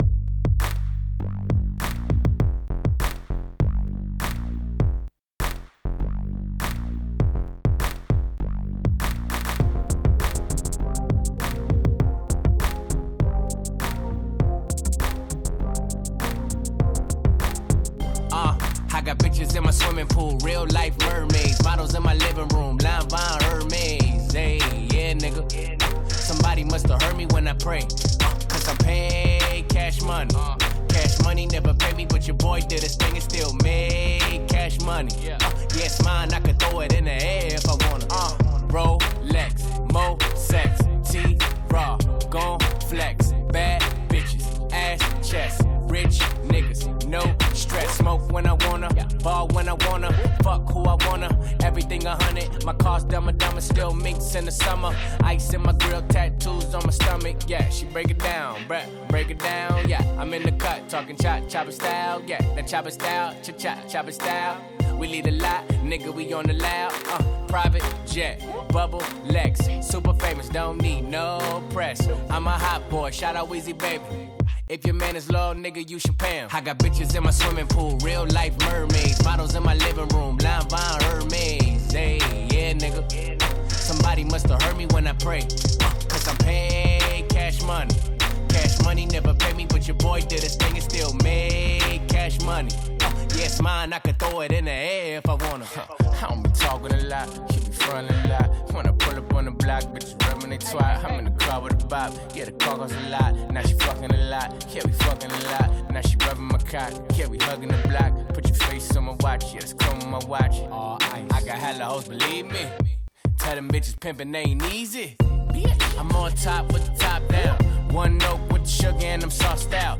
Funky [ 100 Bpm